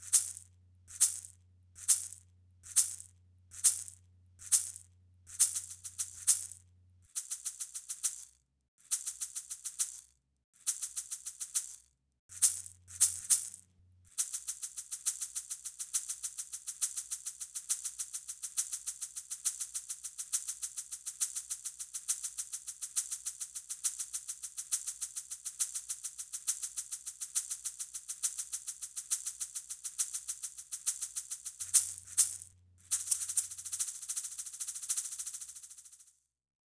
Ampliación de los bancos de sonido digitales de los instrumentos percutidos del folclor Colombiano
Folclor de Colombia, Regiones de Colombia, Instrumentos de percusión, instrumentos musicales